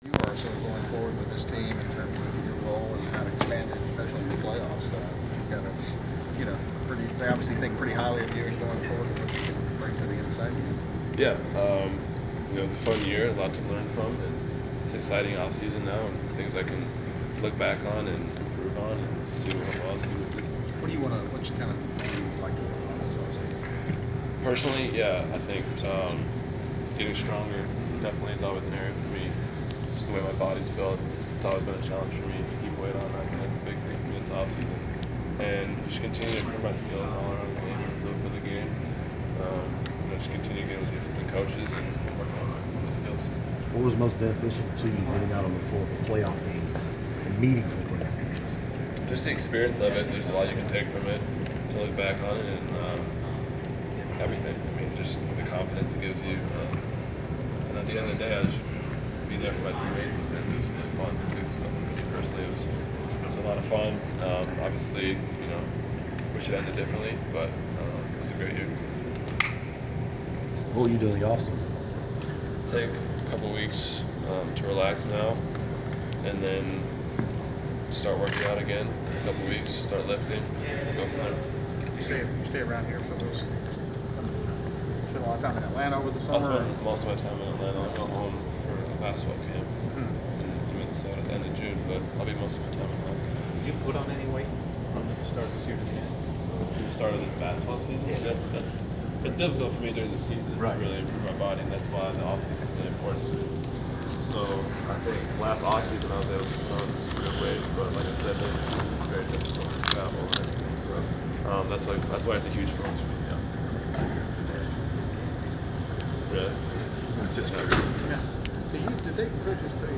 Inside the Inquirer: Postseason presser with Atlanta Hawks Mike Muscala
The Sports Inquirer attended the media presser of Atlanta Hawks forward Mike Muscala following the conclusion of his team’s season. Topics included Muscala’s increased playing time with the Hawks this past season, hopes the team can re-sign free agents DeMarre Carroll and Paul MIllsap and Muscala’s offseason training plans.